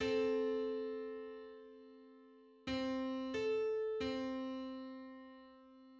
File:Eight-hundred-fifty-fifth harmonic on C.mid - Wikimedia Commons
Public domain Public domain false false This media depicts a musical interval outside of a specific musical context.
Eight-hundred-fifty-fifth_harmonic_on_C.mid.mp3